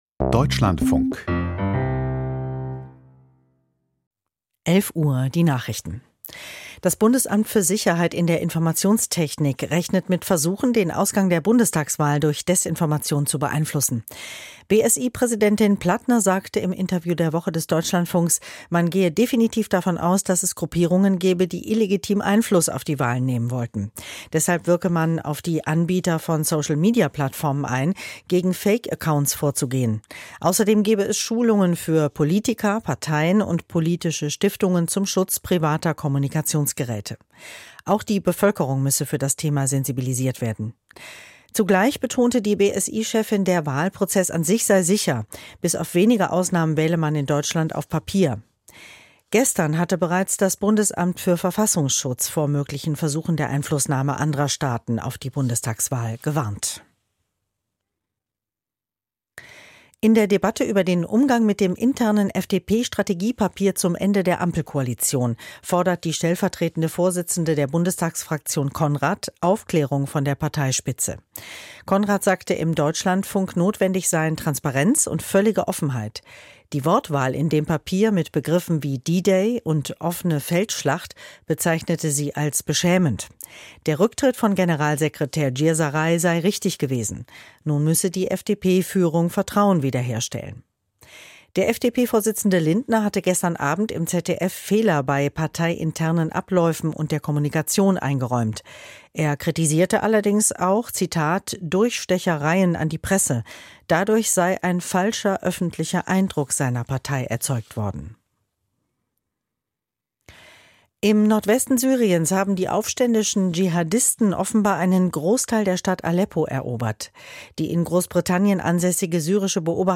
Die Deutschlandfunk-Nachrichten vom 30.11.2024, 11:00 Uhr